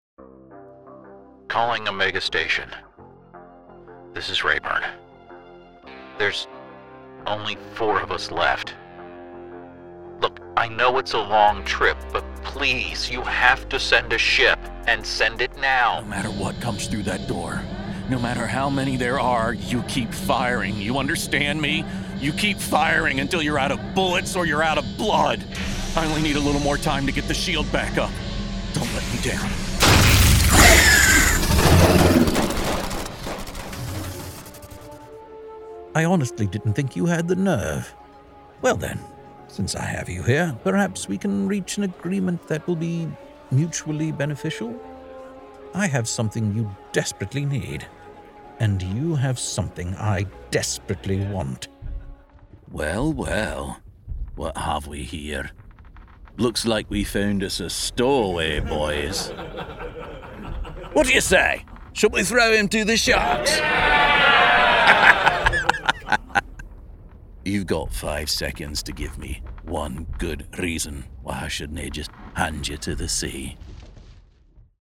Male
Adult (30-50), Older Sound (50+)
Video Game Characters
Words that describe my voice are dynamic, articulate, versatile.